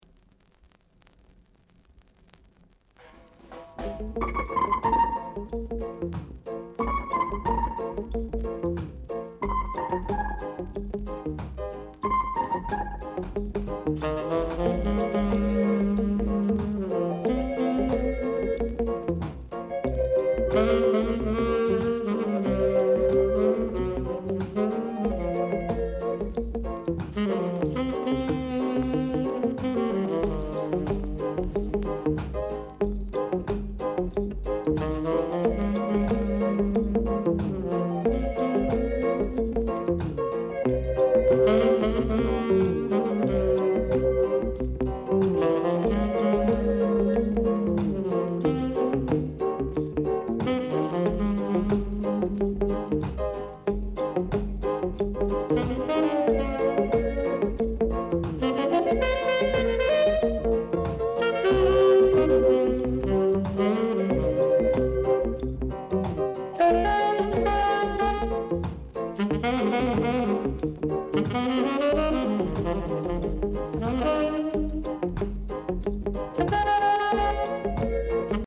R.Steady Vocal Group, Inst
Very rare! great rock steay vocal & inst!